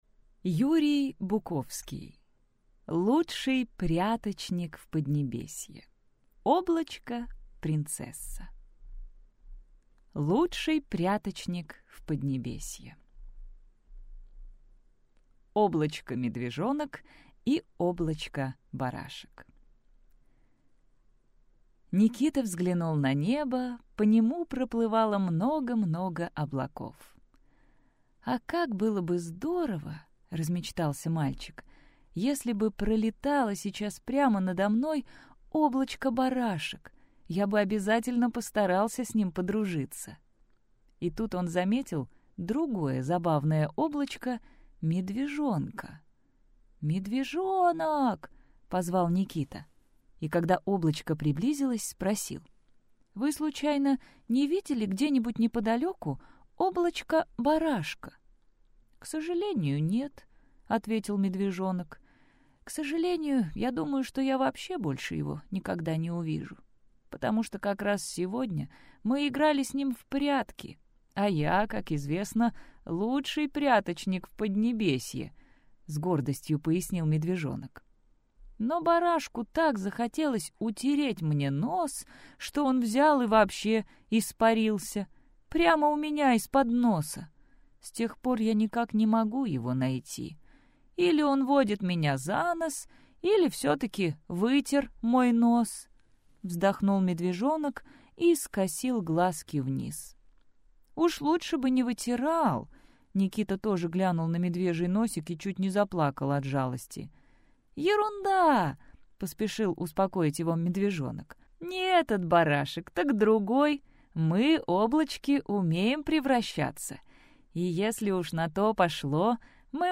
Аудиокнига Лучший Пряточник в Поднебесье. Облачко-Принцесса.